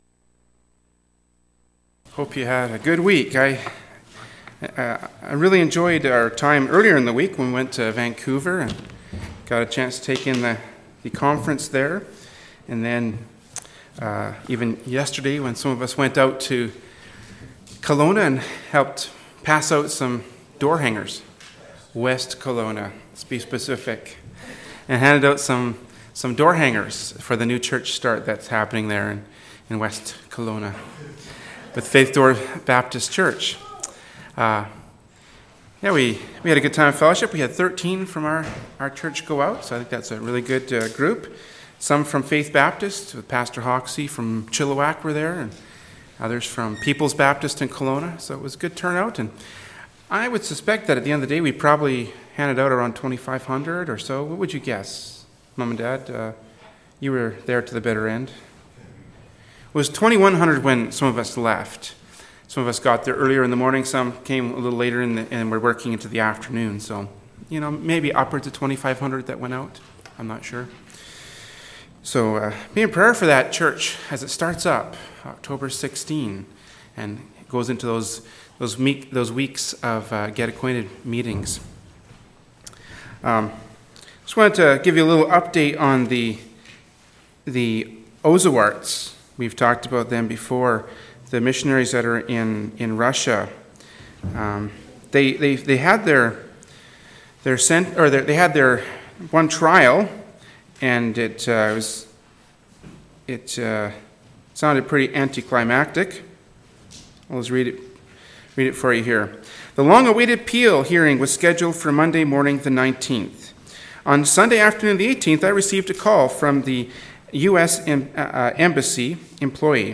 “Proverbs 18:10-15” from Sunday School Service by Berean Baptist Church.